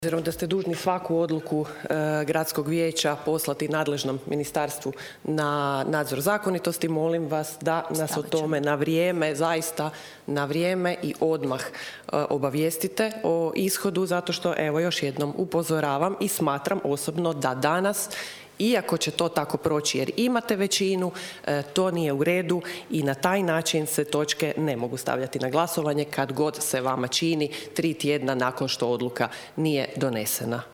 Današnja sjednica Gradskog vijeća Labina započela je više nego zanimljivo.
Federika Mohorović Čekada dodatno je pojasnila svoj stav: (